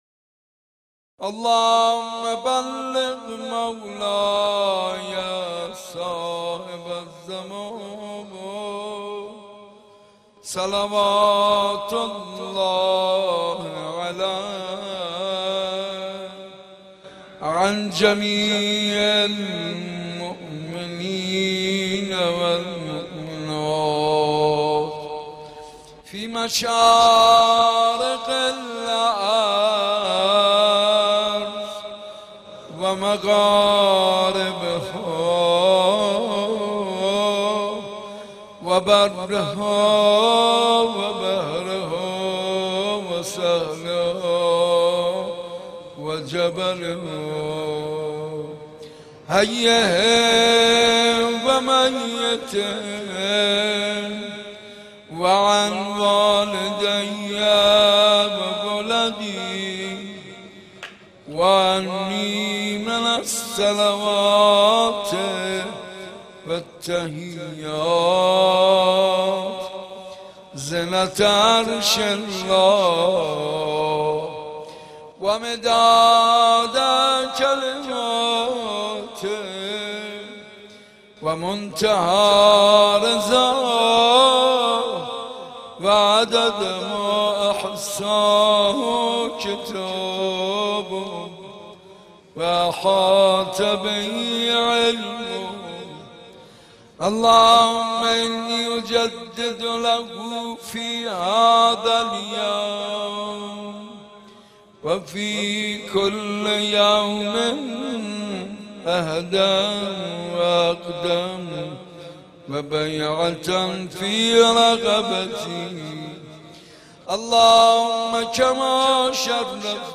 صوت مداحی حاج منصور ارضی در حسینیه صنف لباس فروشان